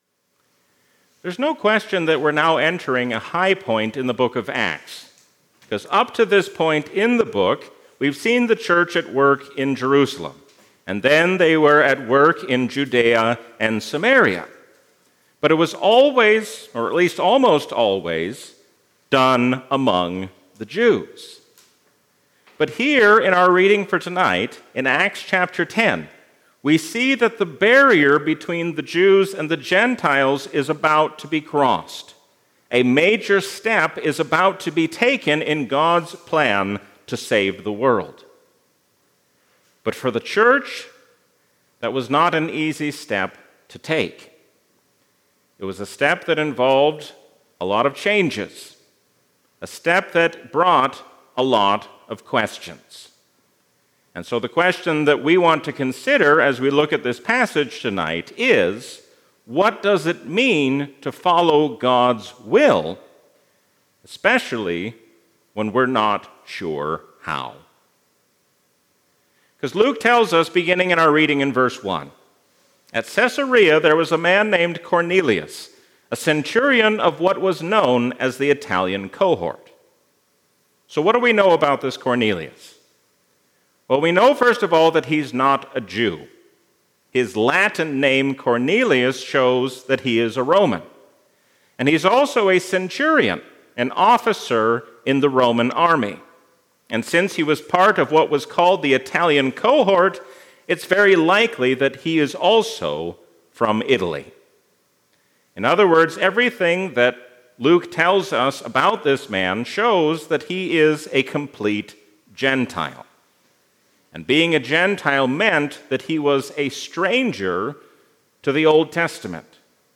A sermon from the season "Trinity 2025." No matter how much the world changes, we can be confident because Jesus does not change.